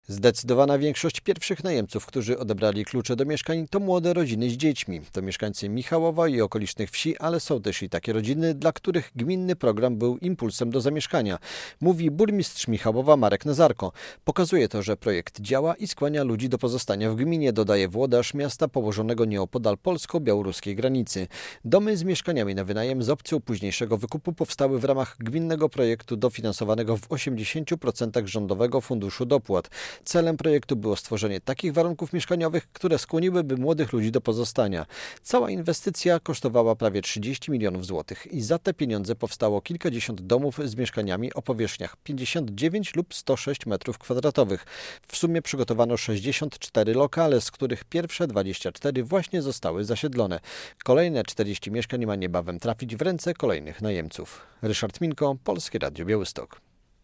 Pierwsi lokatorzy domów w Michałowie - relacja